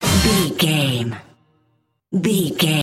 Fast paced
Ionian/Major
Fast
synthesiser
drum machine
Eurodance